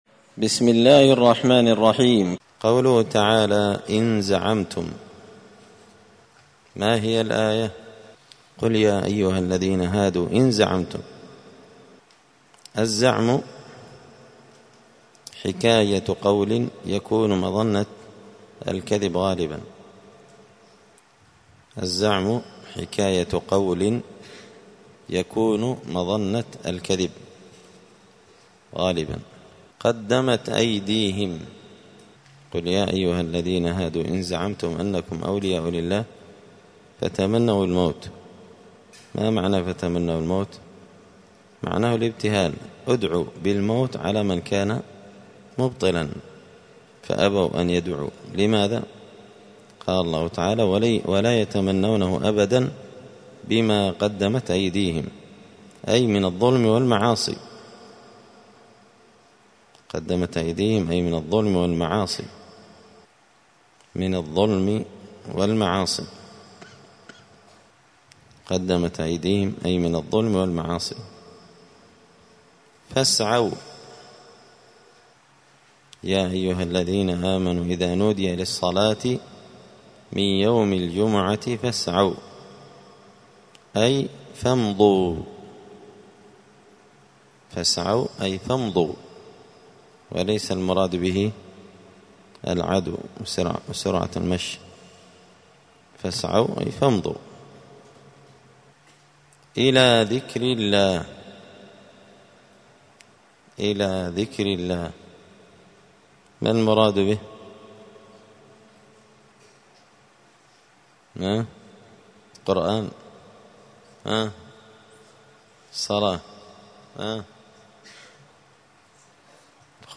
*(جزء المجادلة سورة الجمعة الدرس 145)*
دار الحديث السلفية بمسجد الفرقان بقشن المهرة اليمن